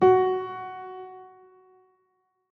🌲 / AfterStory Doki Doki Literature Club game mod_assets sounds piano_keys
F4sh.ogg